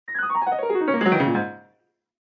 Descarga de Sonidos mp3 Gratis: piano.